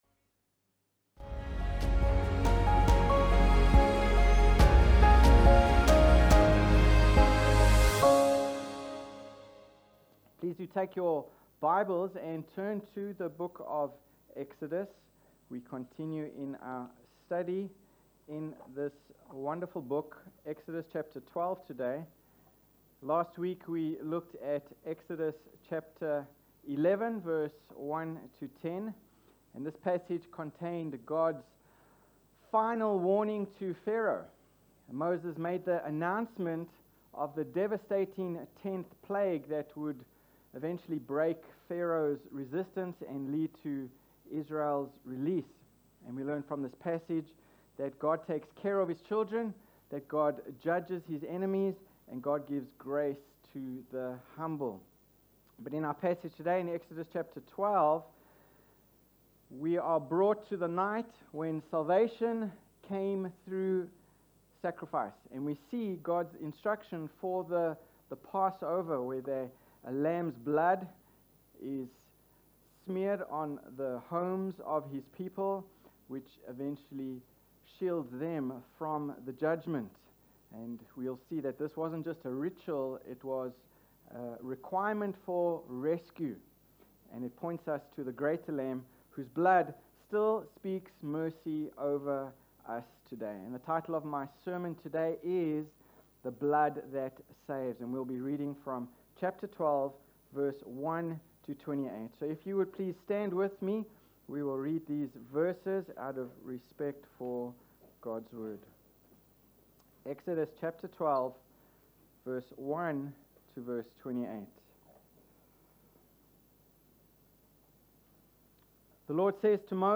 Sermons Podcast - Saved by the Blood | Free Listening on Podbean App